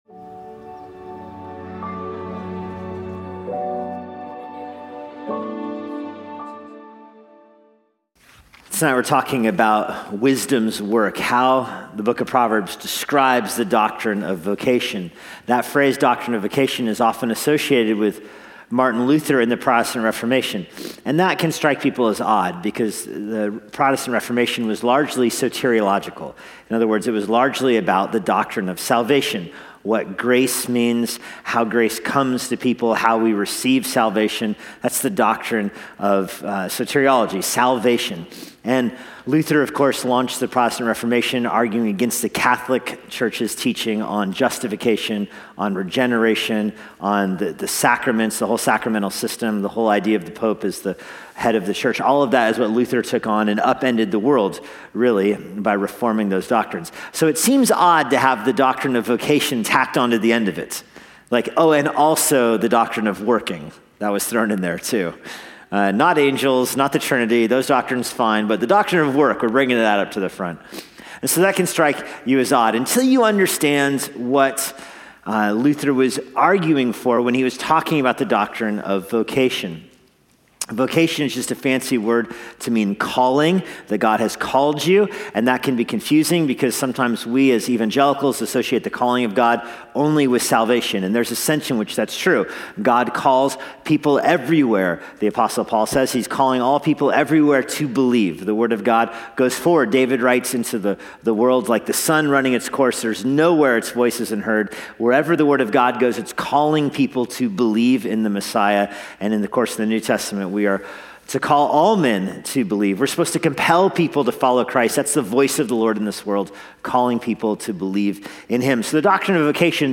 during a worship service at Immanuel Baptist Church, Florence, Ky.